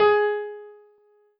piano-ff-48.wav